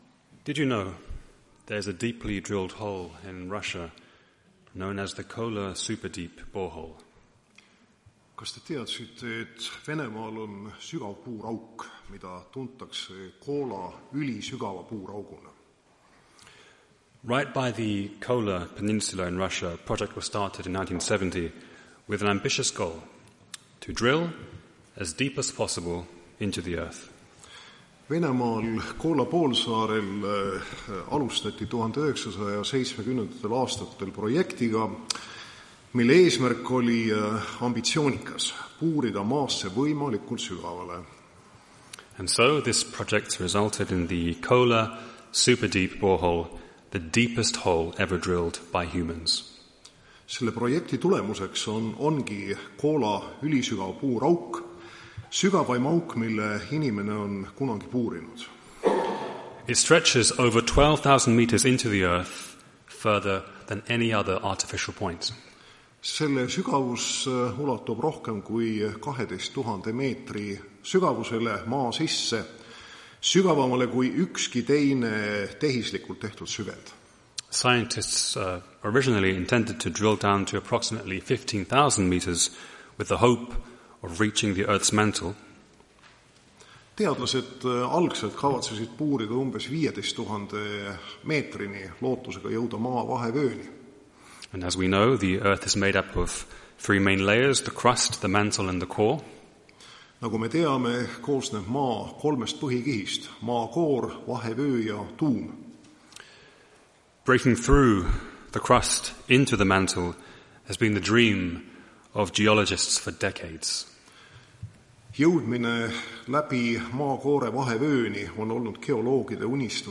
Tartu adventkoguduse 21.09.2024 hommikuse teenistuse jutluse helisalvestis.